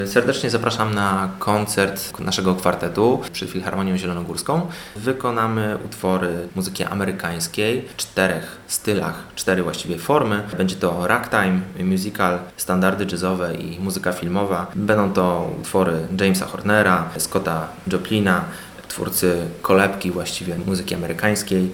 członek kwartetu